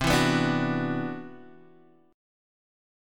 CM#11 chord